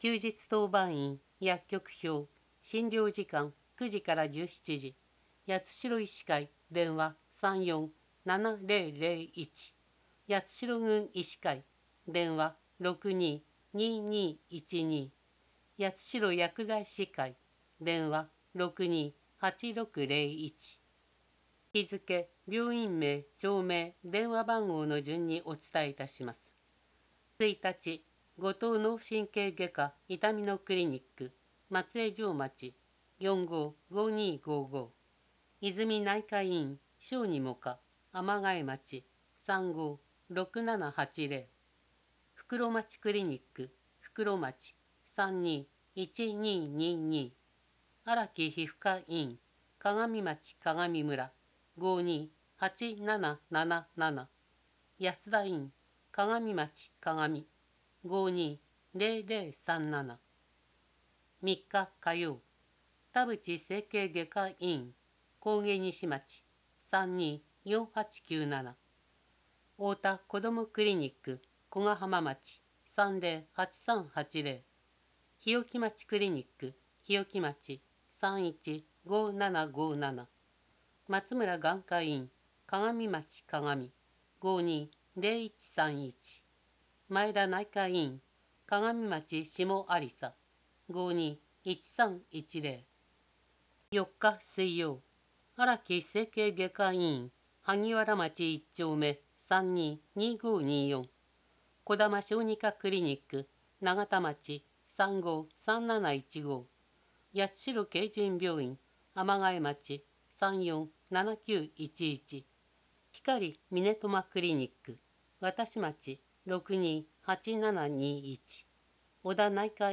声の市報